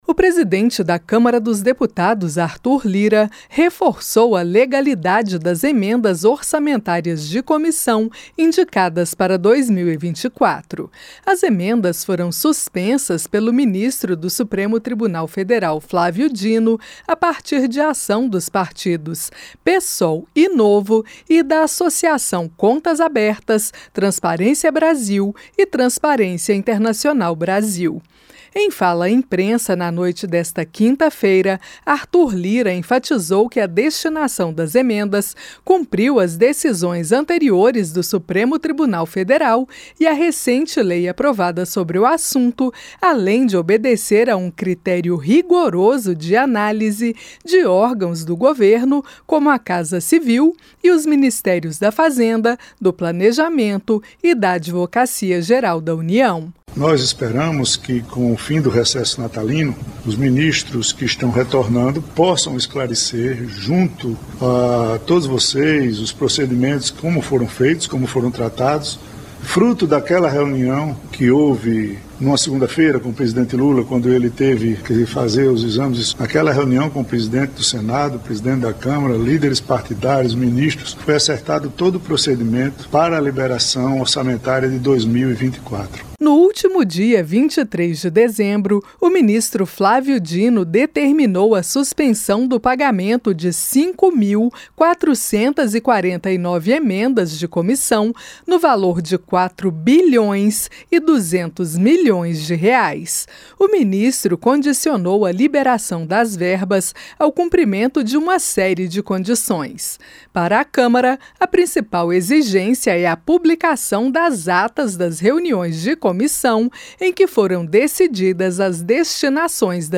LIRA DEFENDE LEGALIDADE NA DESTINAÇÃO DE EMENDAS DE COMISSÃO AO ORÇAMENTO DE 2024. A REPORTAGEM